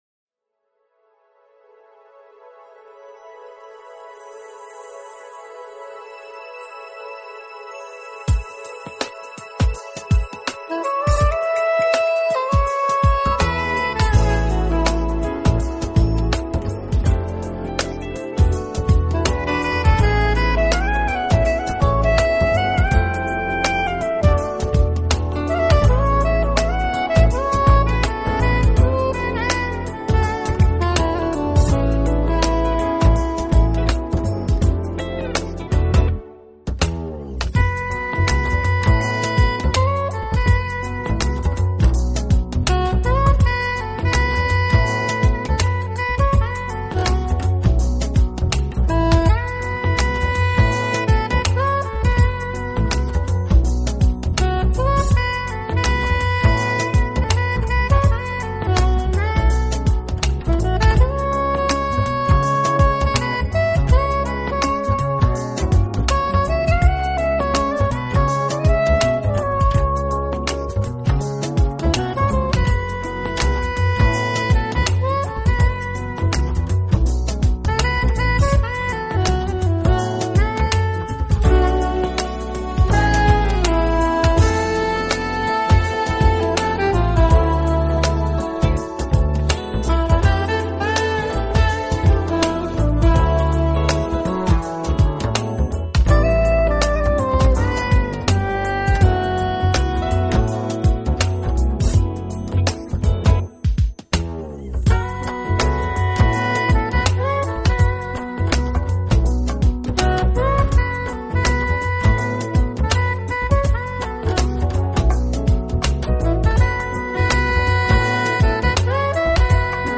Genre: Contemporary Jazz / Saxophone
的音乐充满动感，节奏轻快